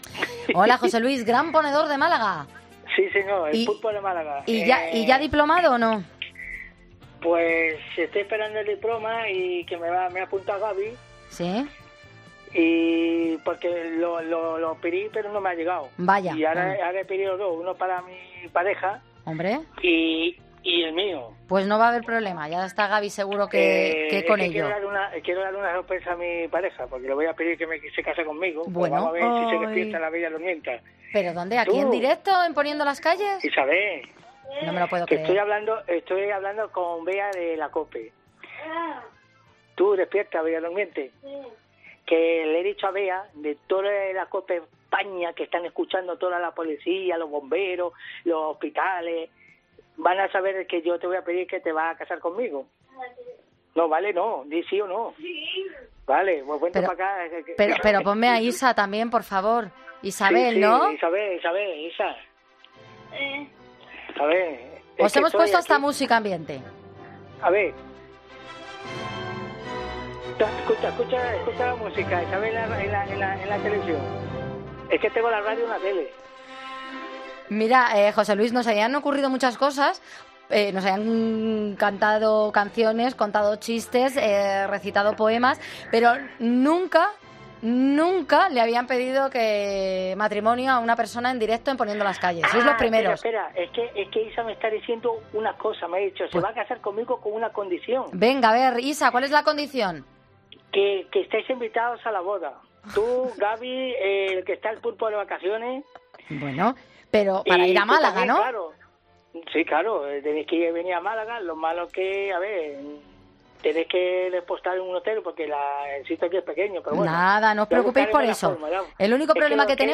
Un oyente pide matrimonio en directo en 'Poniendo las calles'